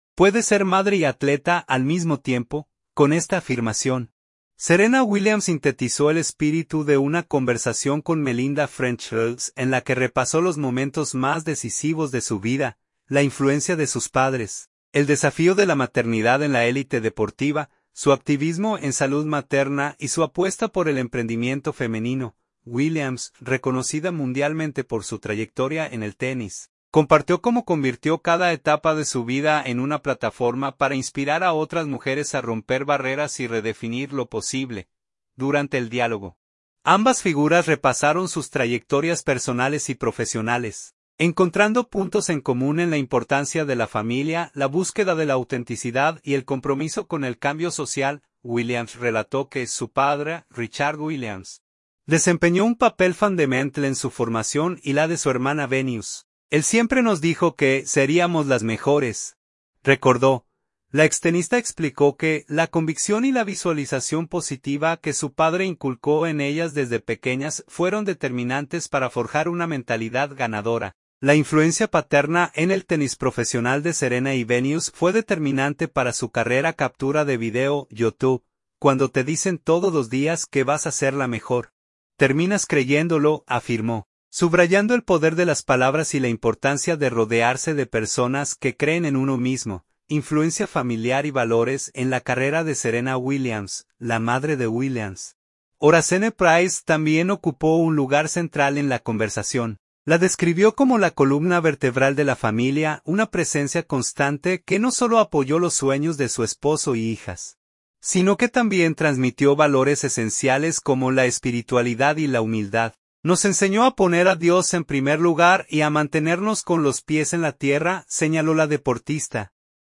Con esta afirmación, Serena Williams sintetizó el espíritu de una conversación con Melinda French Gates en la que repasó los momentos más decisivos de su vida: la influencia de sus padres, el desafío de la maternidad en la élite deportiva, su activismo en salud materna y su apuesta por el emprendimiento femenino.
Durante el diálogo, ambas figuras repasaron sus trayectorias personales y profesionales, encontrando puntos en común en la importancia de la familia, la búsqueda de la autenticidad y el compromiso con el cambio social.